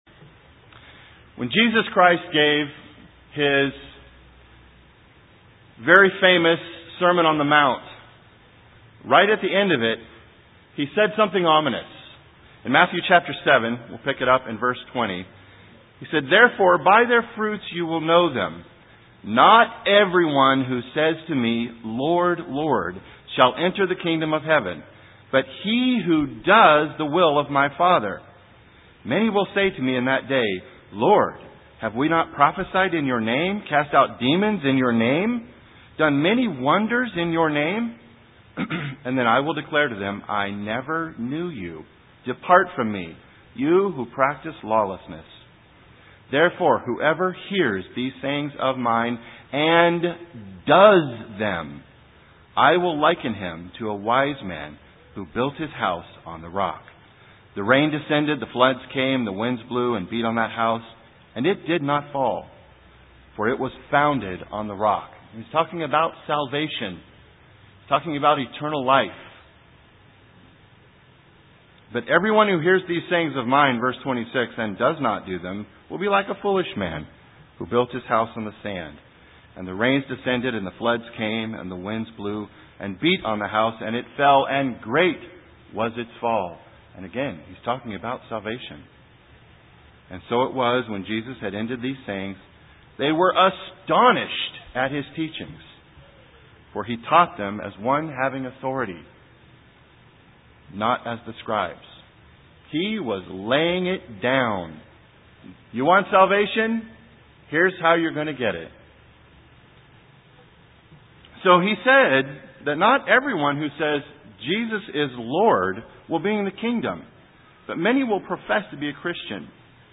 What He asks is that we have a repentant heart. This sermon will help with the understanding of what it means to have a repentant heart.